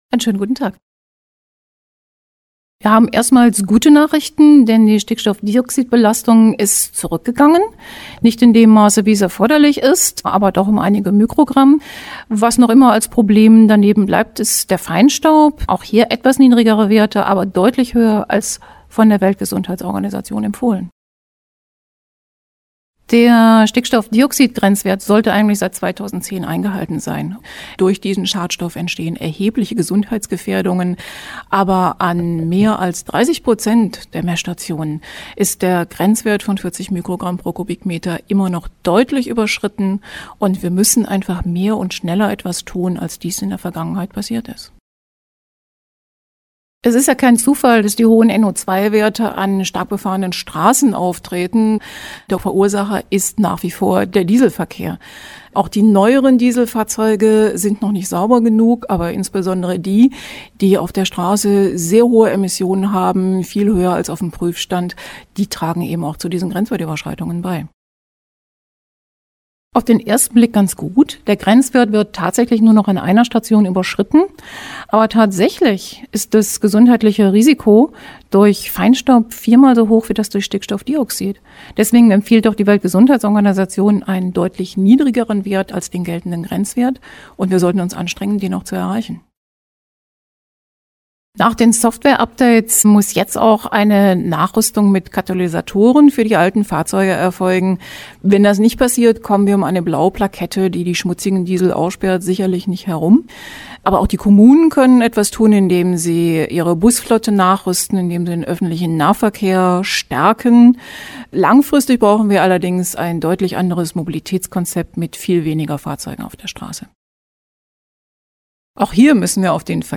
Interview: 2:27 Minuten